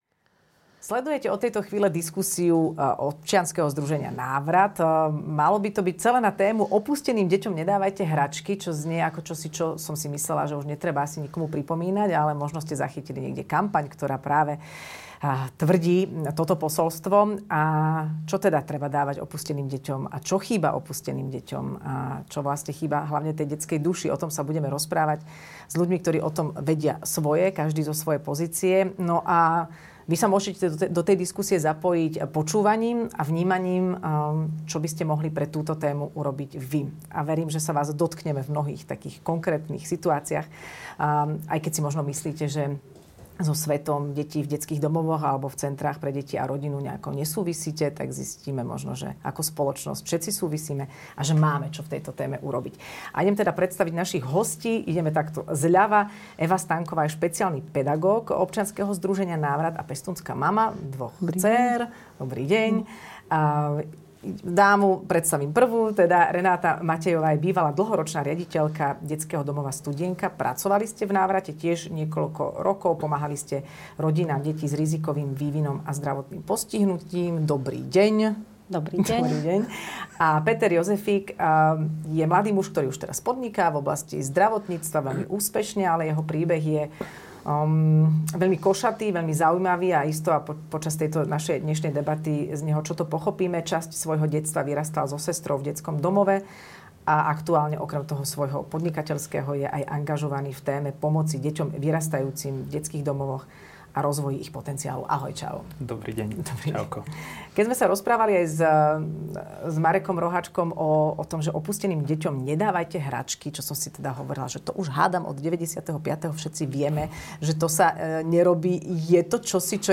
Audio diskusia: Opusteným deťom nerozdávajte hračky
Jedinečná diskusia s ľuďmi, ktorí majú čo povedať k téme, aká pomoc má pre opustené deti zmysel. S hosťami sa rozpráva Adela Vinczeová, ktorá prináša vzhľady aj zo svojich skúseností.